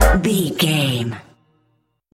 Aeolian/Minor
G#
drum machine
synthesiser
hip hop
Funk
neo soul
energetic
bouncy
funky
hard hitting